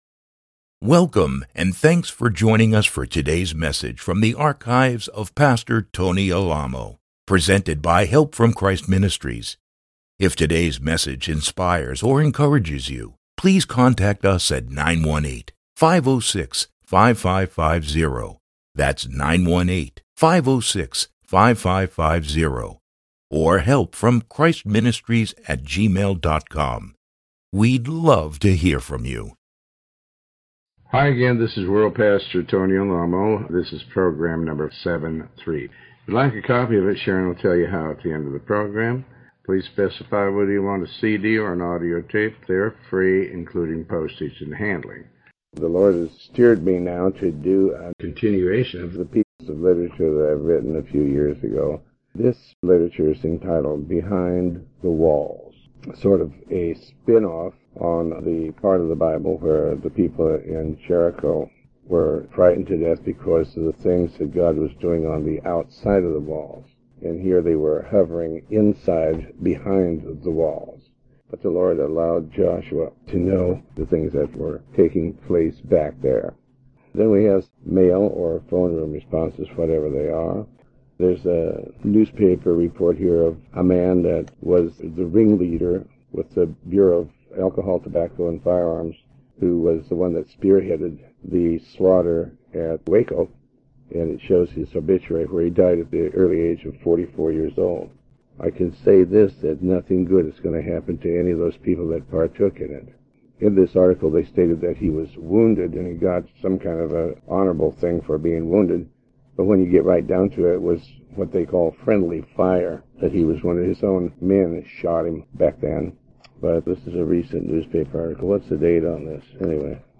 Sermon 73B